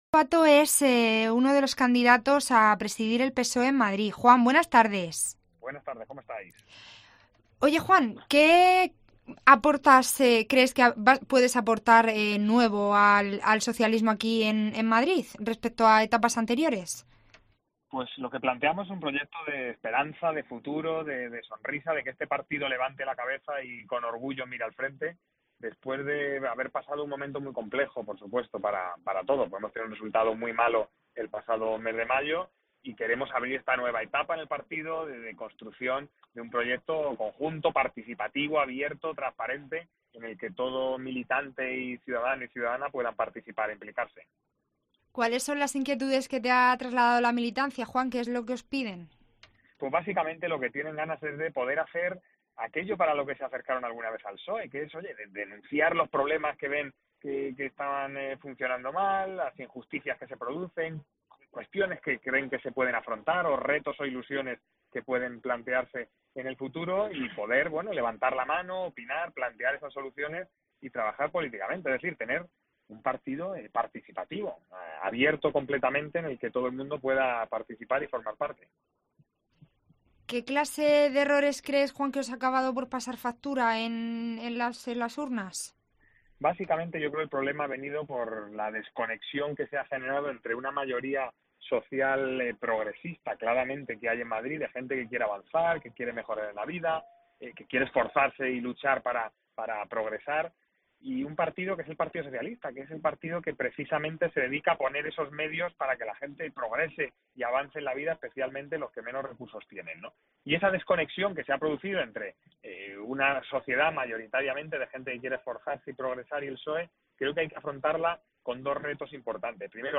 Lobato es uno de los candidatos que aspira a liderar a los socialistas madrileños y ha pasado por los micrófonos de COPE para desglosar sus principales propuestas